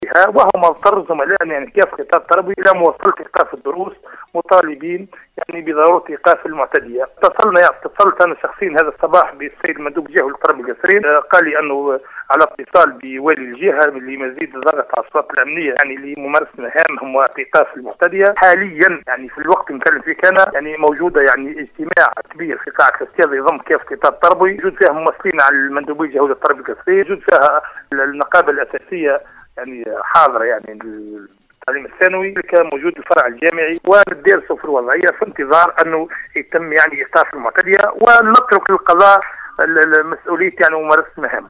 في تدخل هاتفي في برنامج نهارك زين